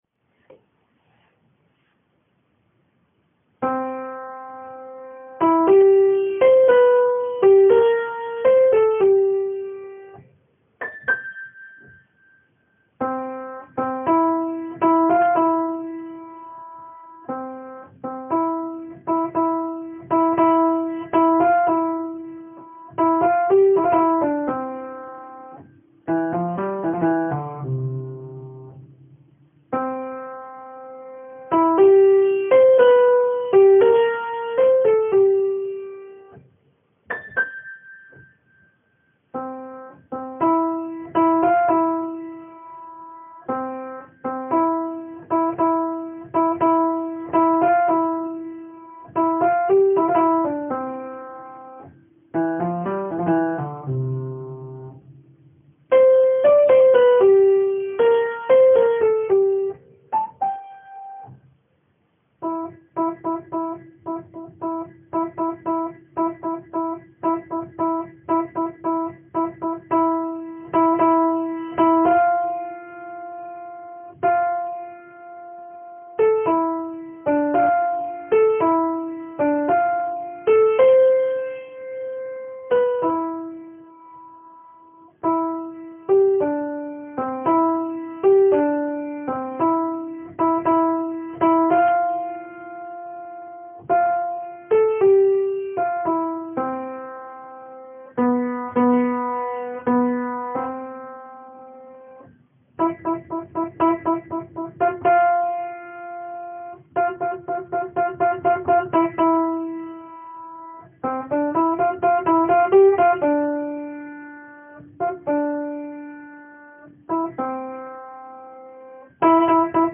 TORROELLA contralts mp3
TORROELLA-contralts-mp3.mp3